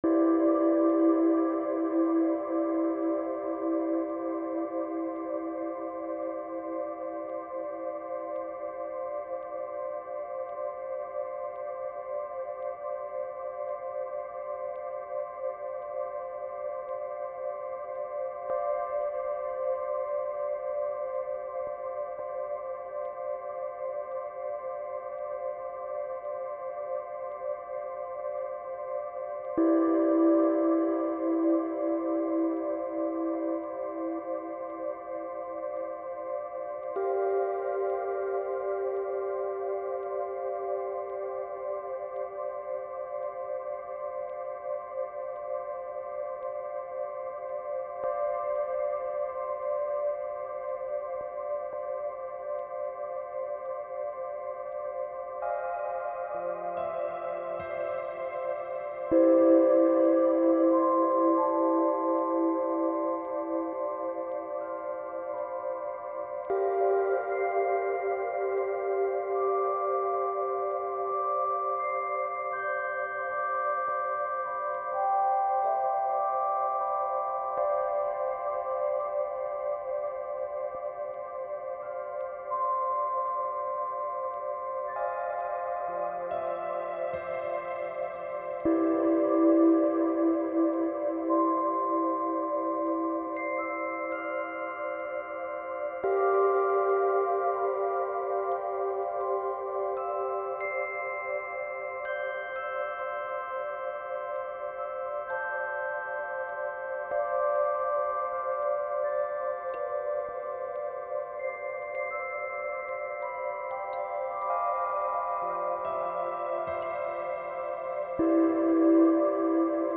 introspective and spatial ambient, of the dark kind